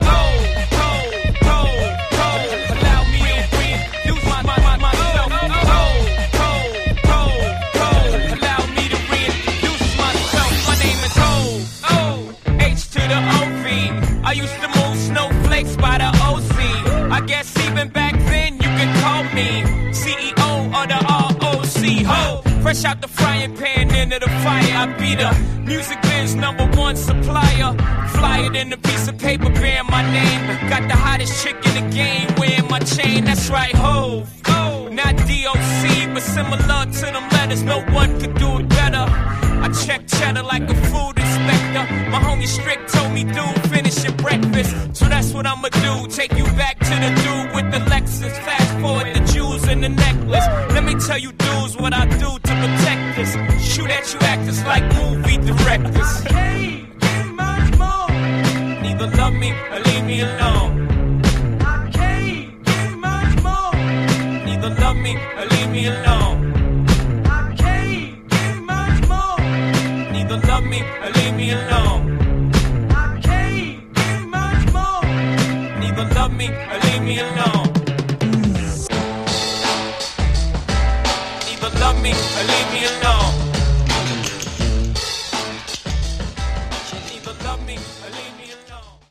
85 bpm